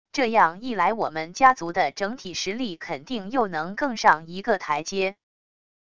这样一来我们家族的整体实力肯定又能更上一个台阶wav音频生成系统WAV Audio Player